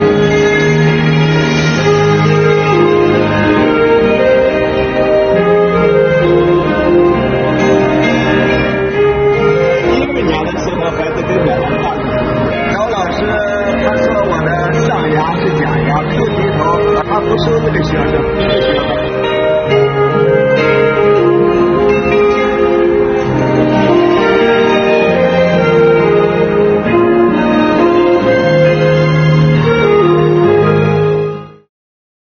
配上萨克斯
优美的旋律
回荡在大街小巷